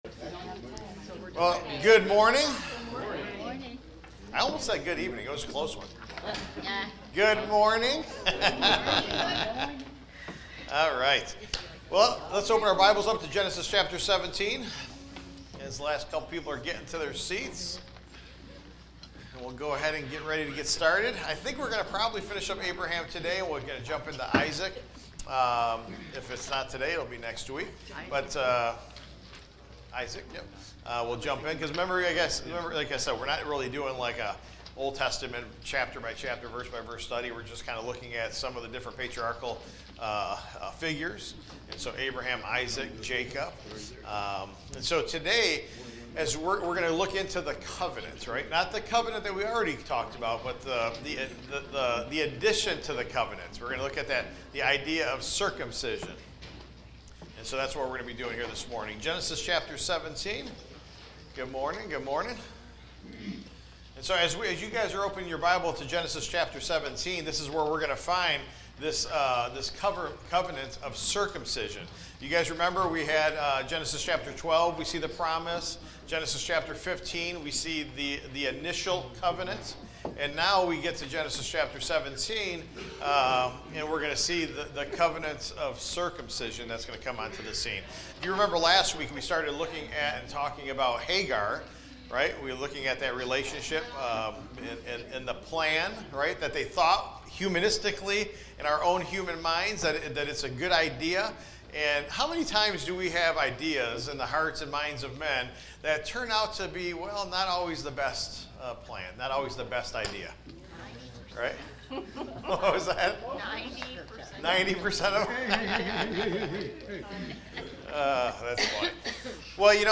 Taught live Sunday, June 29, 2025